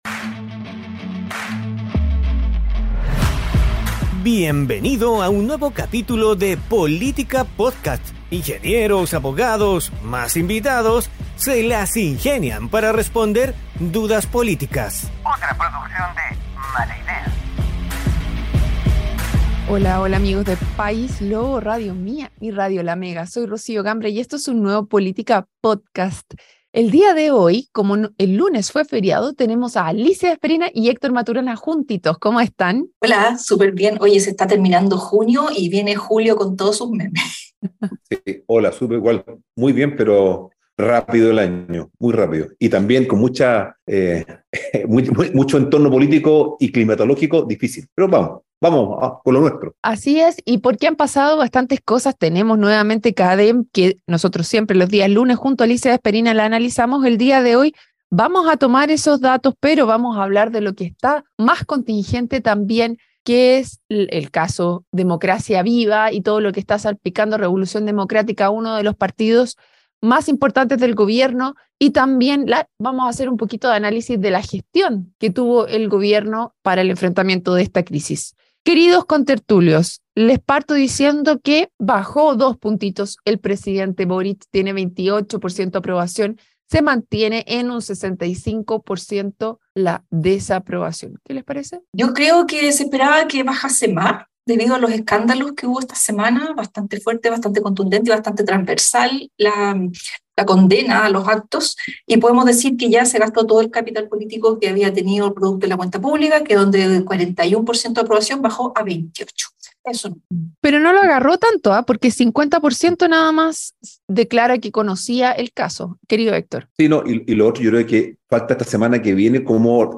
un panel de expertos estables e invitados especiales